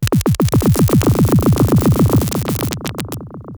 Lickshot02
Lickshot02.wav